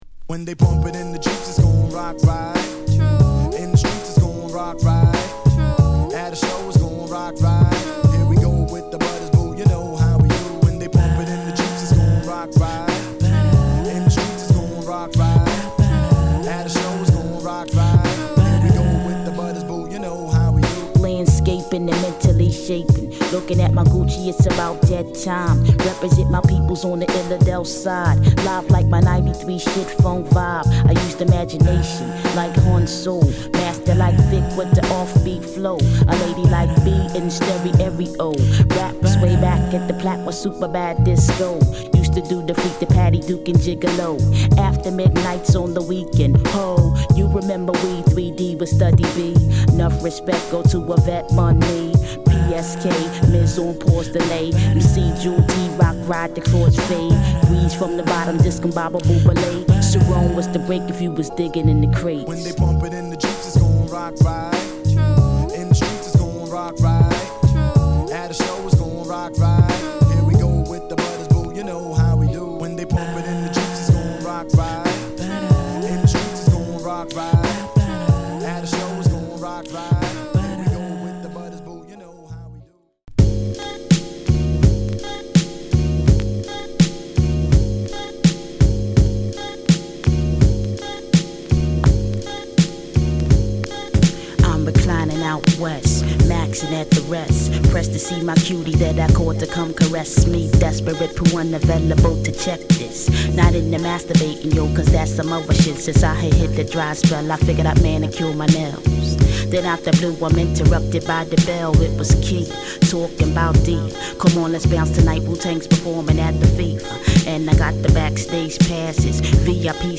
独特の語り口ラップが癖になる！